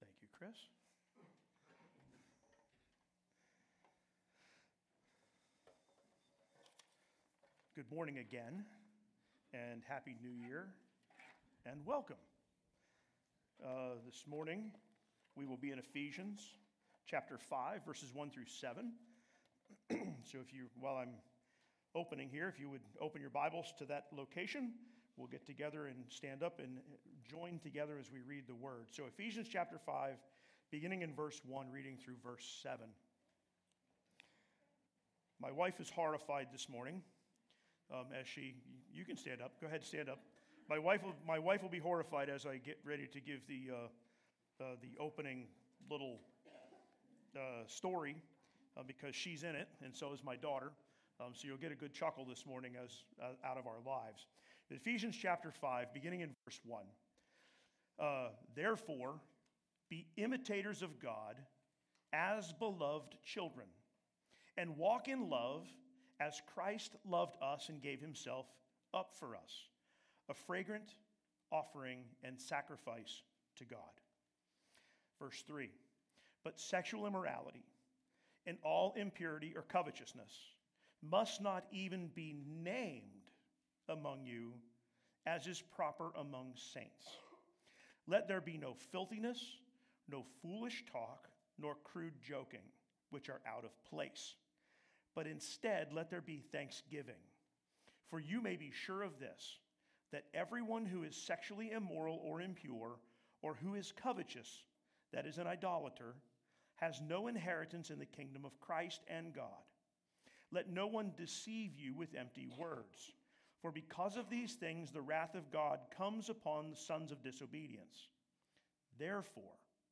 SERMONS | Sunbury City Church
Current Sermon